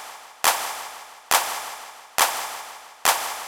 CLP REVERB-R.wav